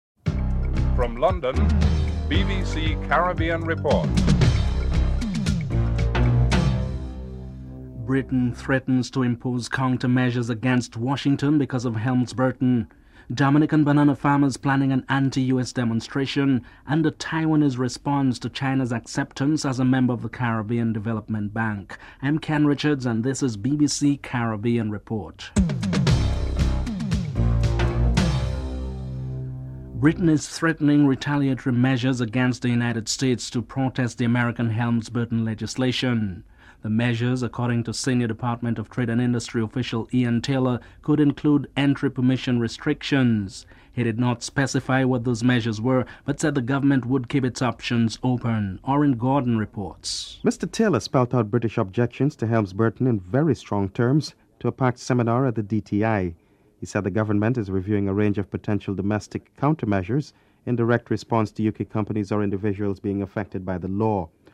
1. Headlines (00:00-00:28)
A Miami-based lawyer is interviewed (13:15-14:39)